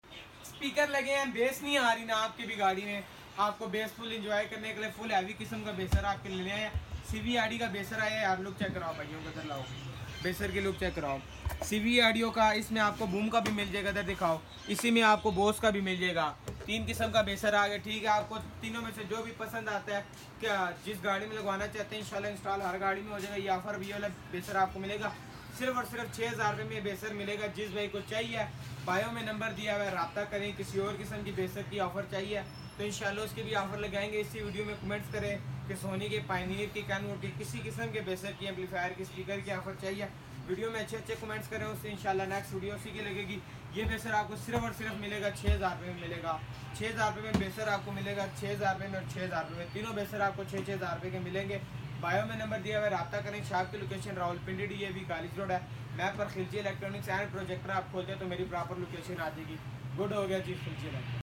Car 12 inche subwoofer high sound effects free download